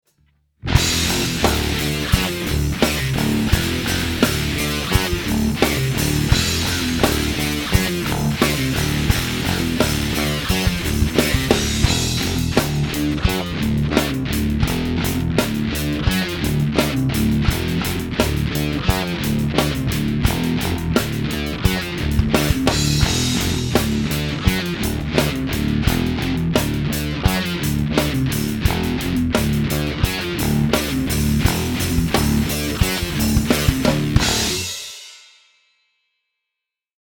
When you spend any time studying rock guitar